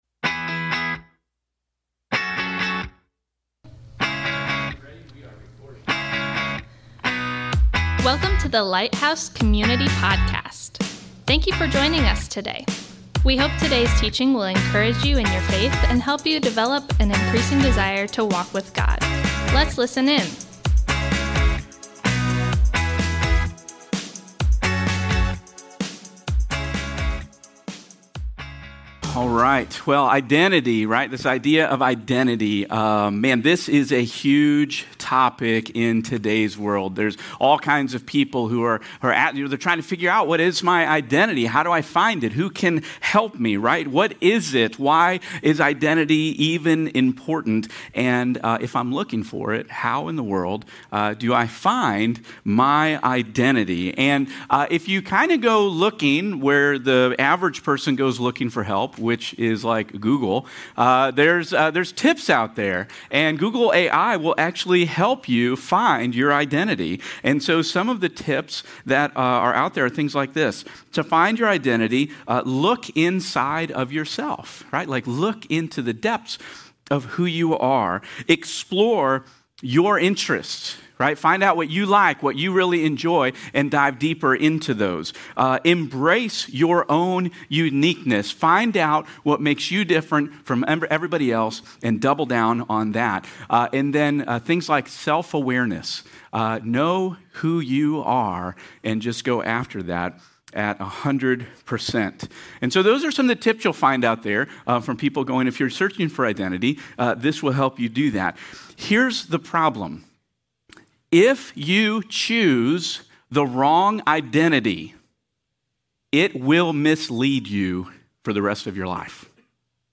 Through this series we will be digging into what scripture has to say about who we are in Christ and how to find our identity in Christ. Today our sermon is titled, "I Am New In Christ".